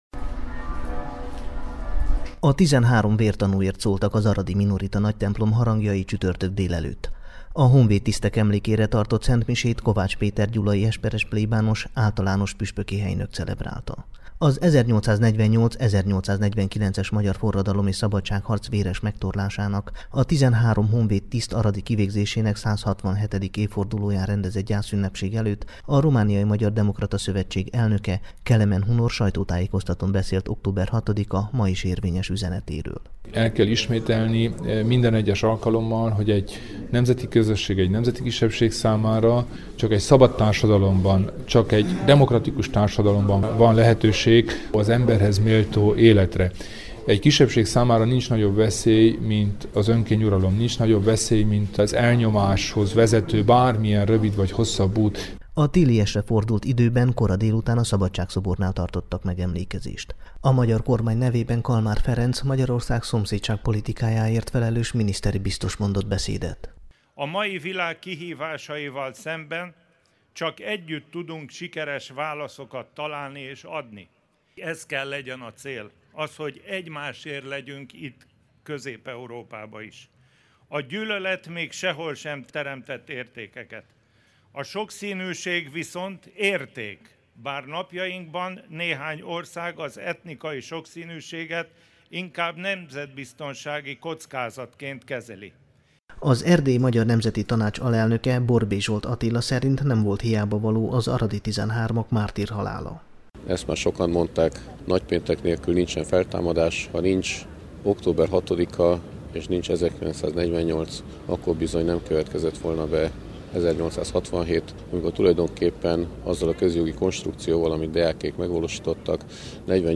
Gheorghe Falcă polgármester is köszöntötte a hideg és a reggel óta kitartó szitáló, de a koszorúzásra elállt esős idő ellenére is szép számban megjelenteket.